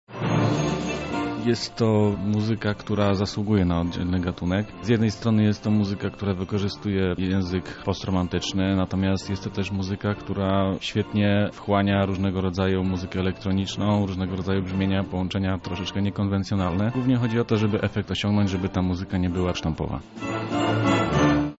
Prawdziwą muzyczną ucztę dla kinomanów i miłośników klasyki podano w niedzielę w postaci Koncertu muzyki filmowej.